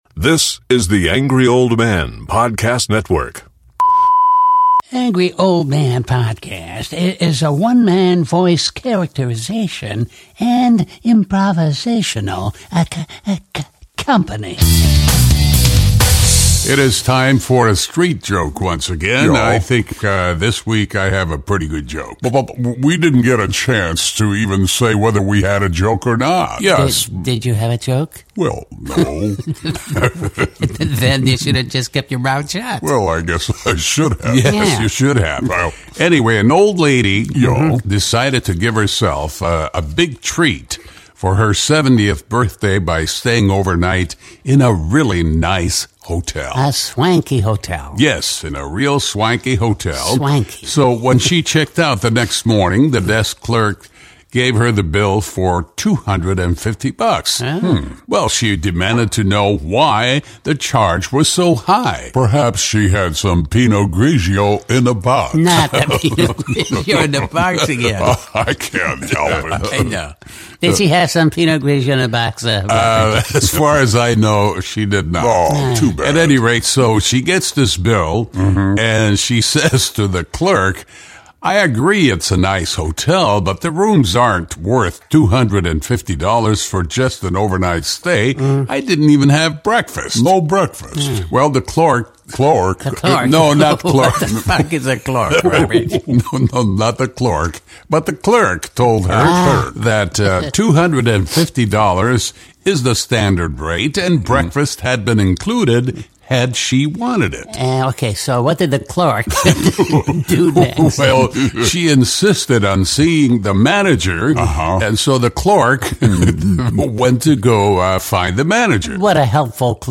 A one man voice characterization and improvisational company.